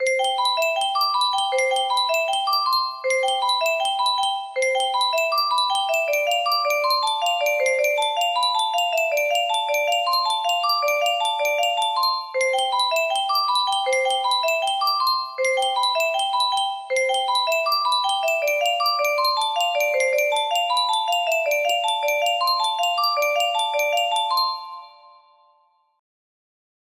Saturn 30 F scale - Untitled music box melody
Grand Illusions 30 (F scale)